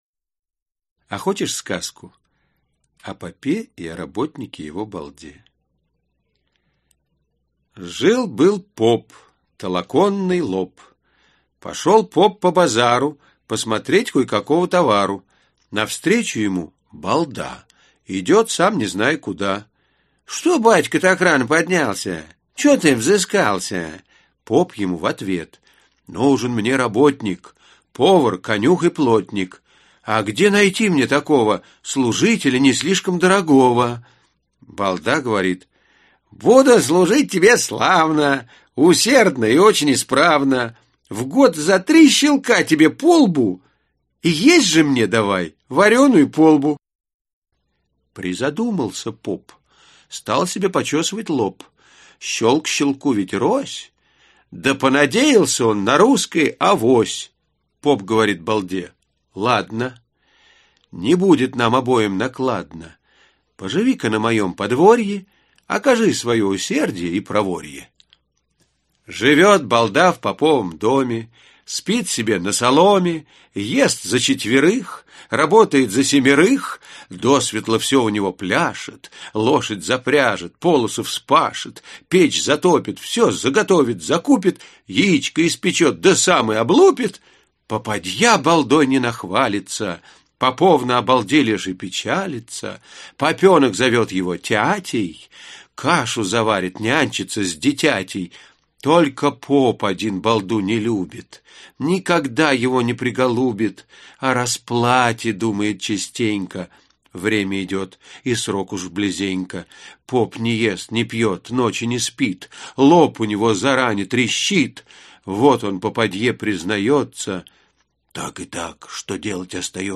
Аудиокнига Сказка о рыбаке и рыбке (сборник) | Библиотека аудиокниг
Aудиокнига Сказка о рыбаке и рыбке (сборник) Автор Александр Пушкин Читает аудиокнигу Вениамин Смехов.